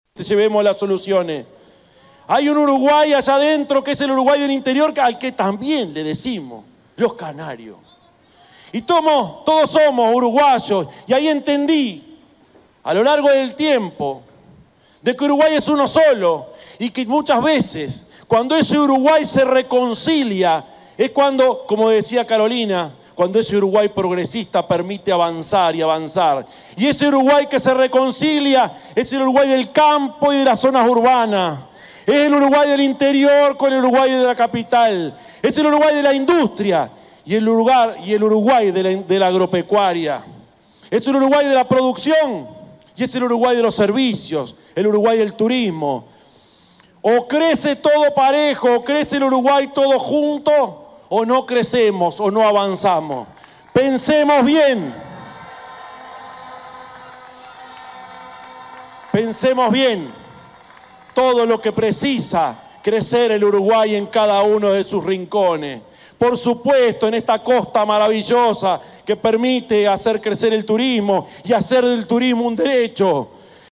El candidato presidencial del Frente Amplio, Yamandú Orsi en el acto de cierre de campaña en Las Piedras reivindicó su condición de “canario” y señaló que “el interior pide a gritos que llevemos soluciones”.
1-orsi-acto-de-cierre.mp3